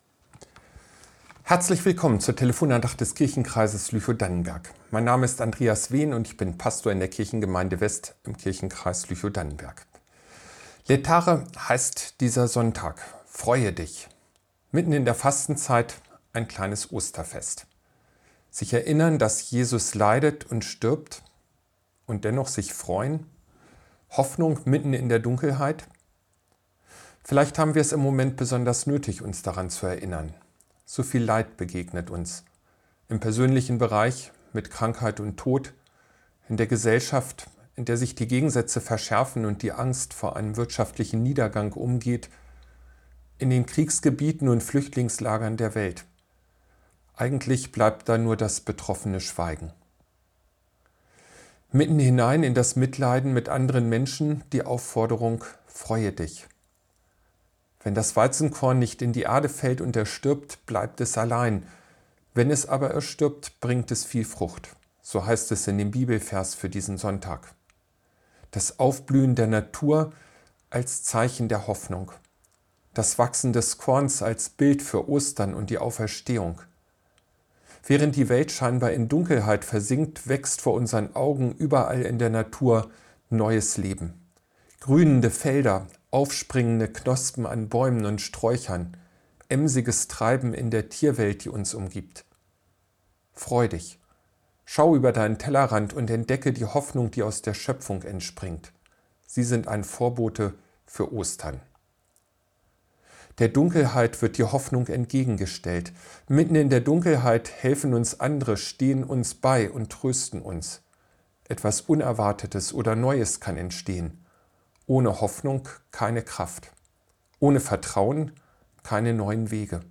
Telefon-Andachten des ev.-luth. Kirchenkreises Lüchow-Dannenberg